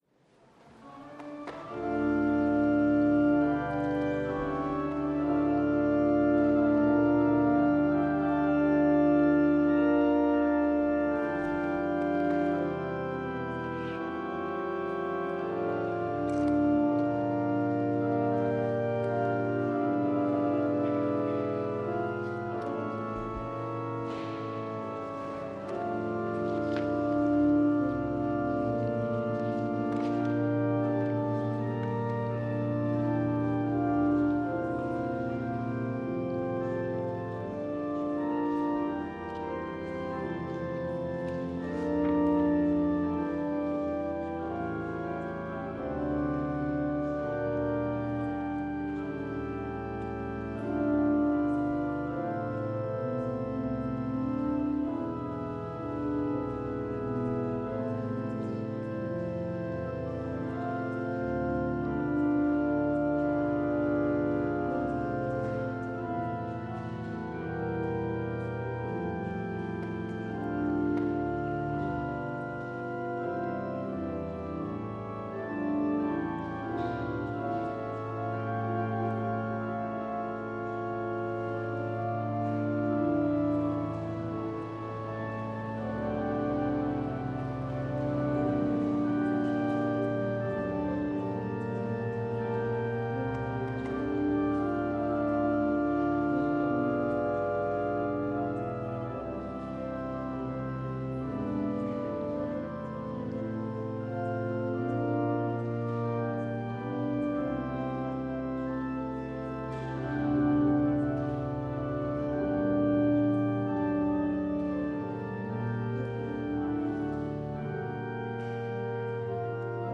Szentháromság-vasárnap